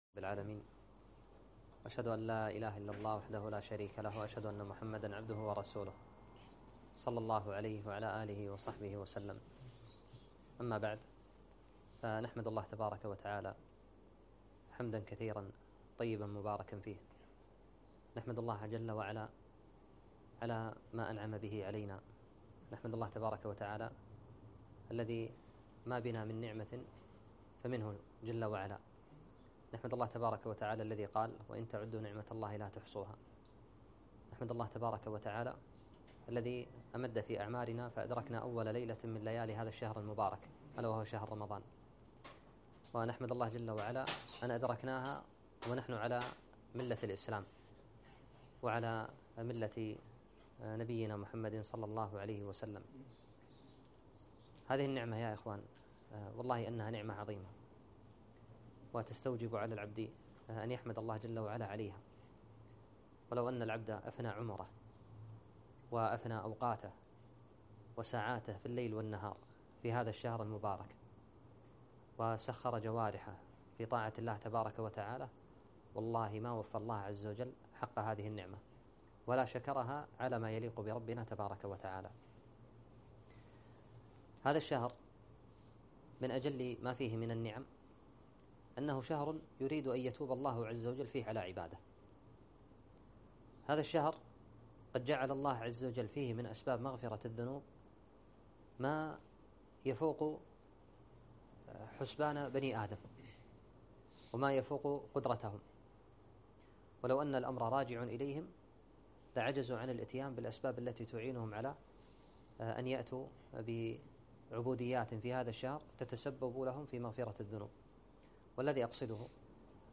محاضرة - (وسارعوا إلى مغفرة من ربكم)